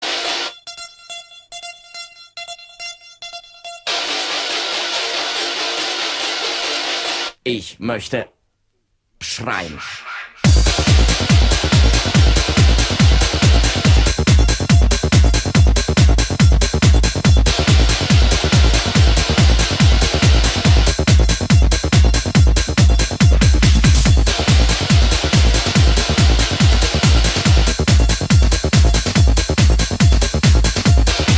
schwein.mp3